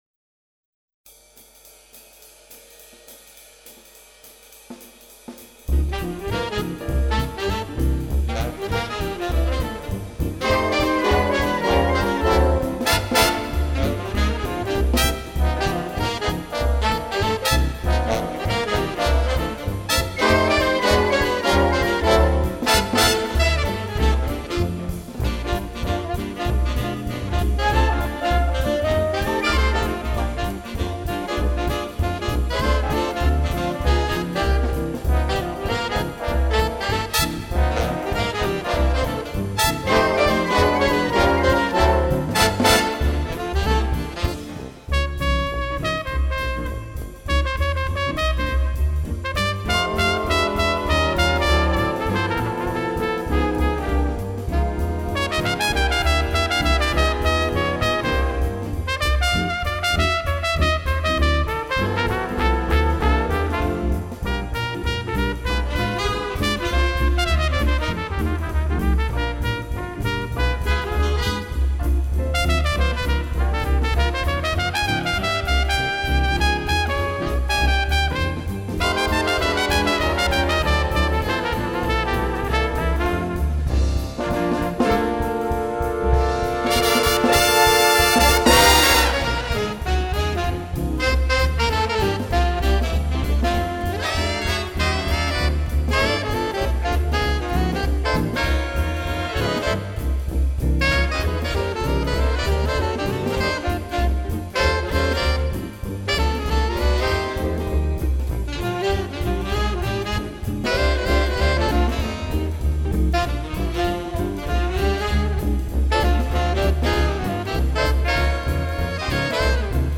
Audio clip wind band